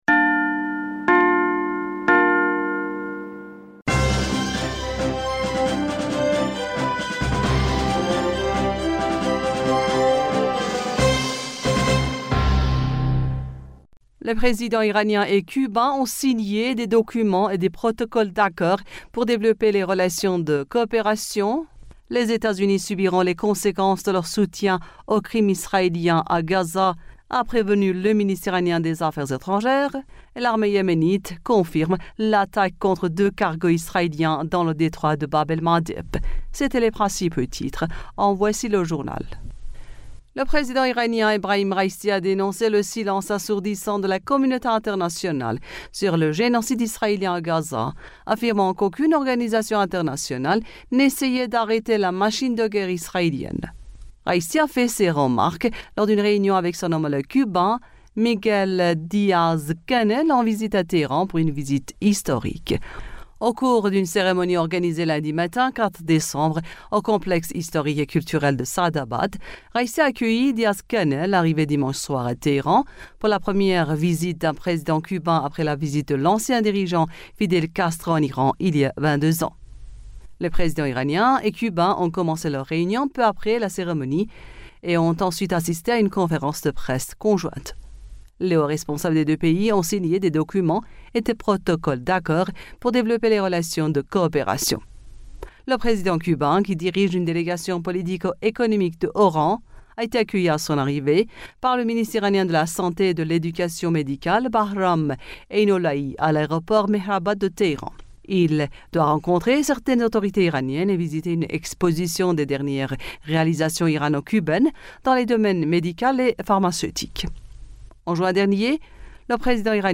Bulletin d'information du 04 Decembre 2023